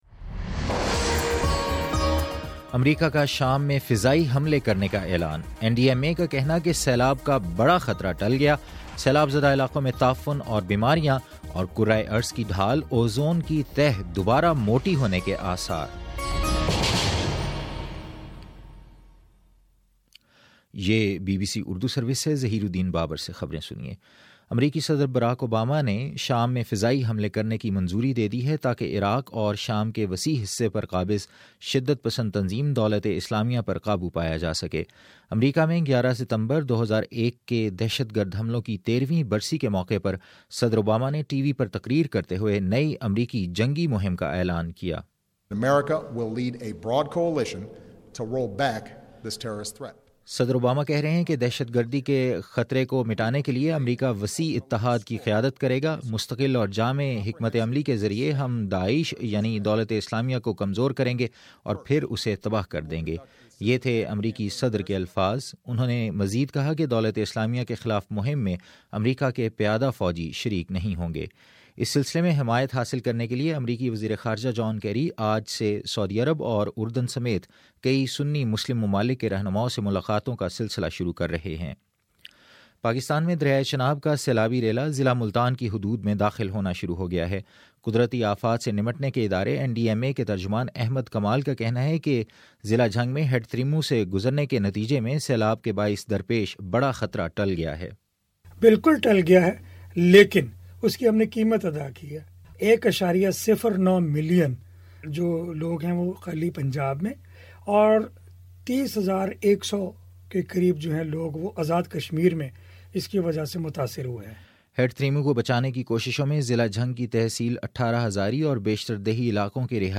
جمعرات 11 ستمبر: صبح نو بجے کا نیوز بُلیٹن
دس منٹ کا نیوز بُلیٹن روزانہ پاکستانی وقت کے مطابق صبح 9 بجے، شام 6 بجے اور پھر 7 بجے۔